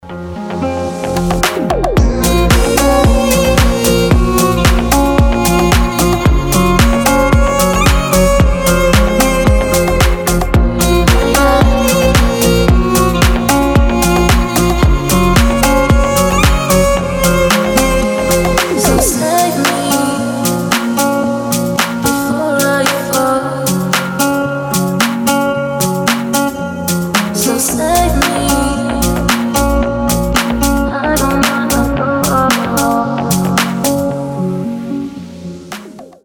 • Качество: 320, Stereo
гитара
женский вокал
deep house
восточные мотивы
красивая мелодия
скрипка
Cover